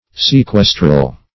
sequestral - definition of sequestral - synonyms, pronunciation, spelling from Free Dictionary Search Result for " sequestral" : The Collaborative International Dictionary of English v.0.48: Sequestral \Se*ques"tral\, a. (Med.)
sequestral.mp3